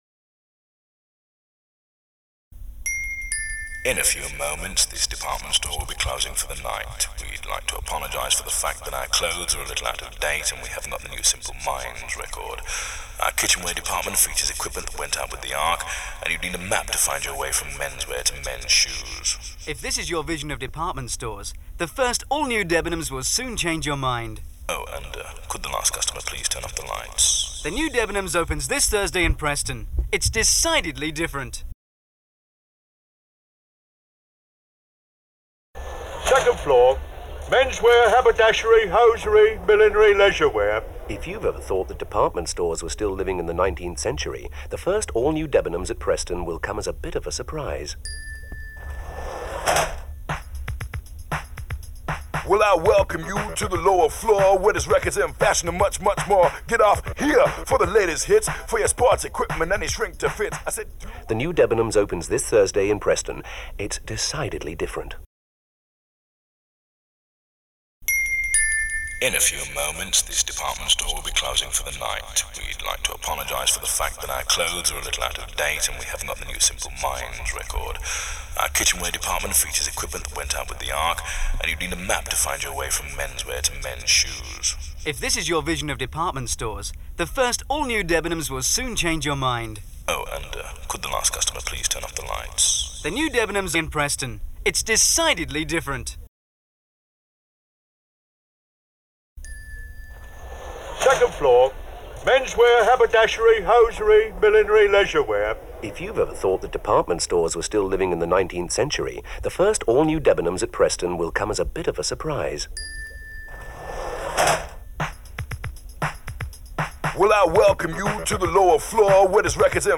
Debenhams Radio Ads